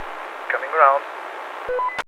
描述：“直接命中。”模拟战斗机飞行员对话。
Tag: 电台 战争 语音 战斗喷气机 军事 语言 飞机 飞行员 样品 飞机 要求 战斗 男性